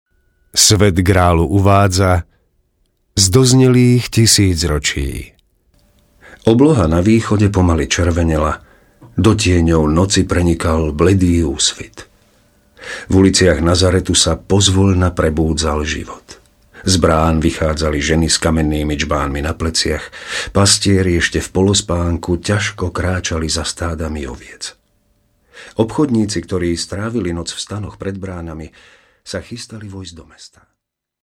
Professioneller slovakischer Sprecher für TV / Rundfunk / Industrie / Werbung.
Sprechprobe: Werbung (Muttersprache):
Professionell male slovakian voice over artist